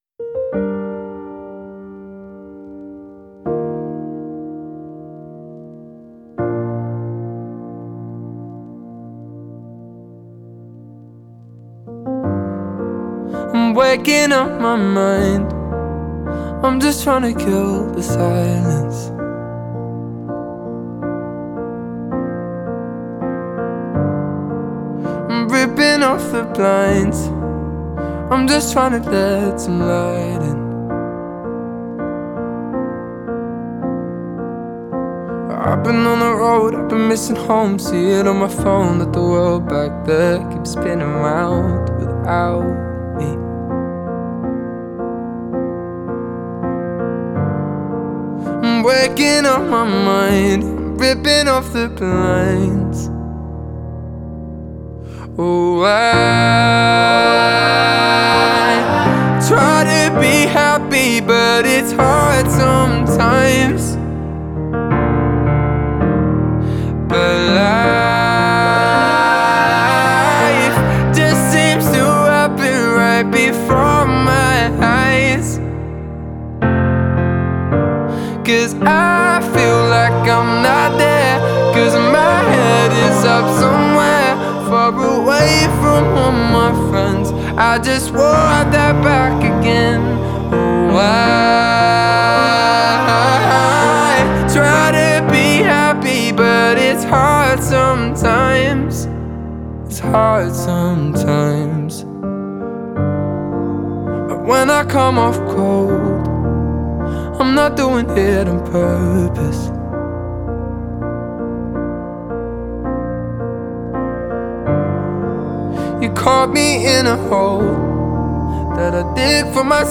Genre: Neo-Soul, Pop